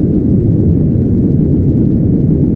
techage_gasflare.ogg